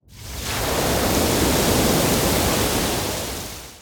Free Fantasy SFX Pack
Wave Attack 1.wav